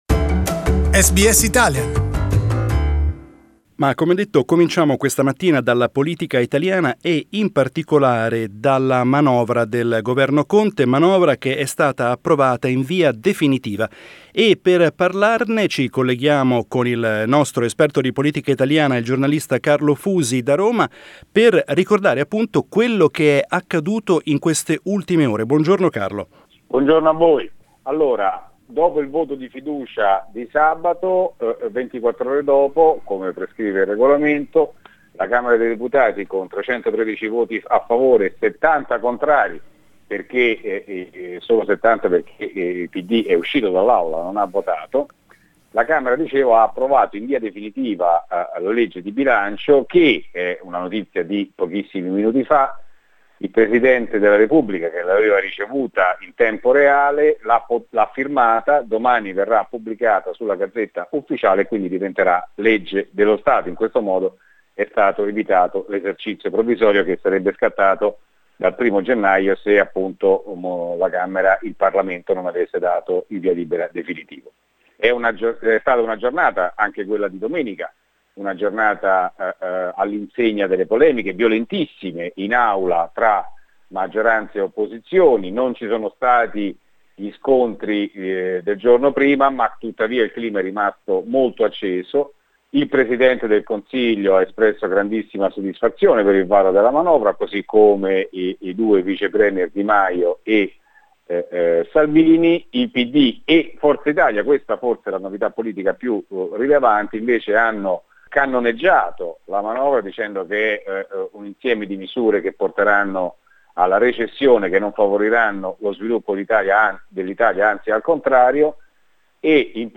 Our analysis with journalist